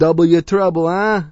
gutterball-3/Gutterball 3/Commentators/Louie/l_doubleyoustrouble.wav at 58b02fa2507e2148bfc533fad7df1f1630ef9d9b